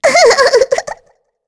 Shea-Vox_Happy3_kr.wav